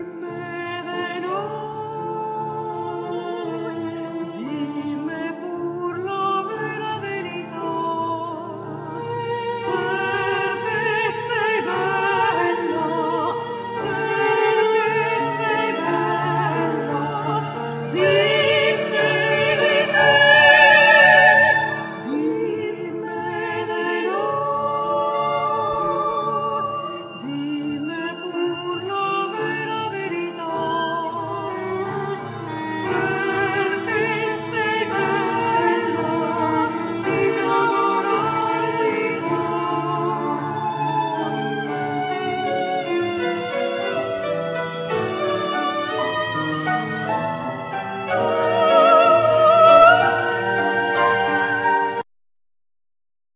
Vocal
Violin
Cello
Piano
Chorus
Percussions
Keyboards
Tambura,Violin,Mandolin
Flute,Clarinetto,Percussions
Harmonica
Guitar